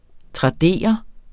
Udtale [ tʁɑˈdeˀʌ ]